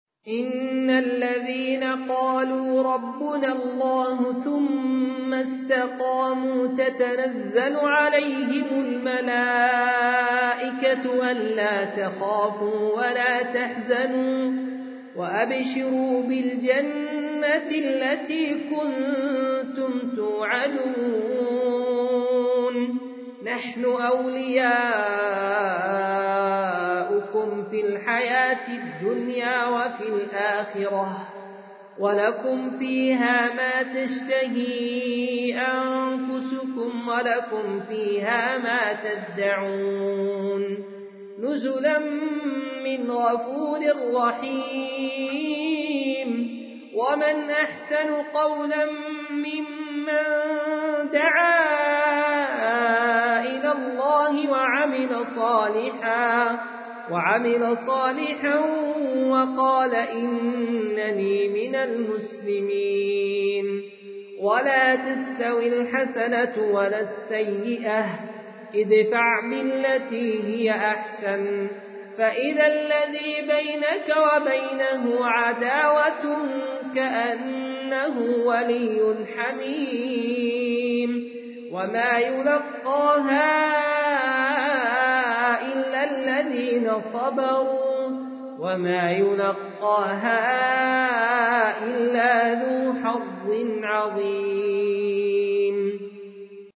ما تيسر من سورة فصلت من صلاة العشاء.mp3